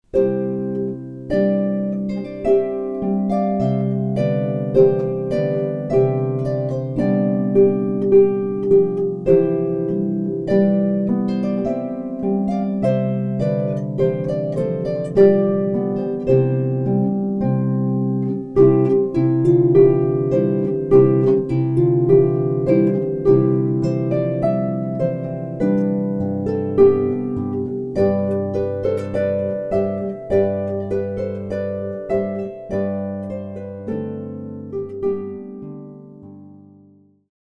Sample wedding music, well suited to the harp